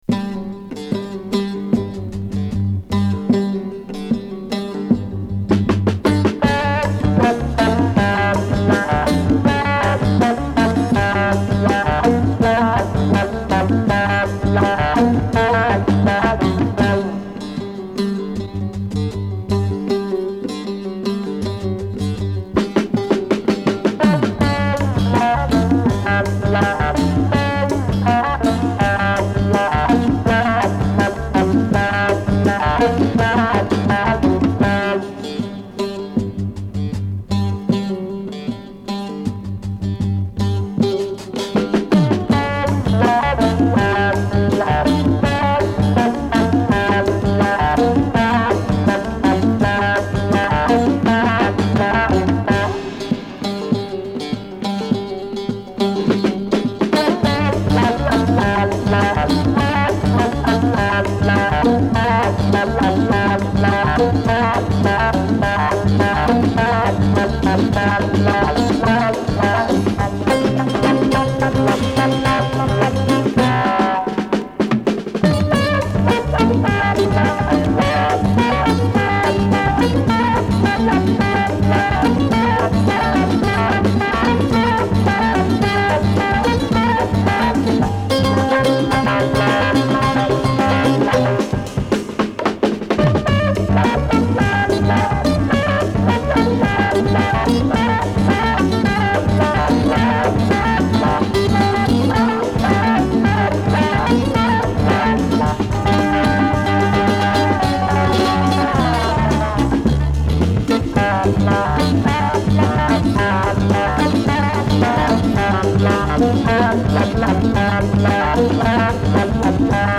turkish psych rock dirge